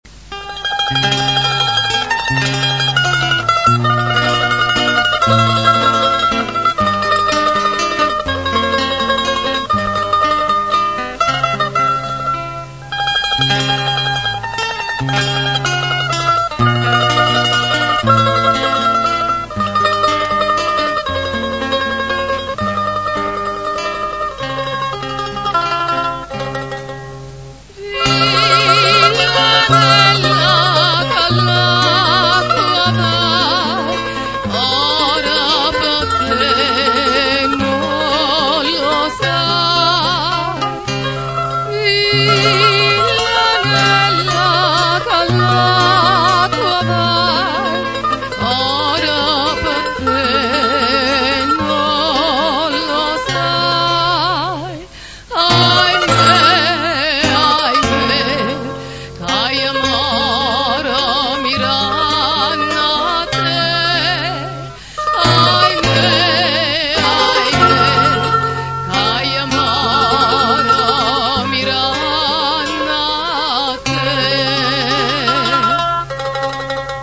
villanella
Ascolta Classiche vesuviane cantate da " Napoli Antica" Se non si ascolta subito il suono attendere qualche secondo, solo la prima volta
VILLANELLA.mp3